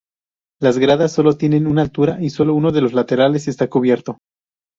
Pronounced as (IPA) /ˈɡɾadas/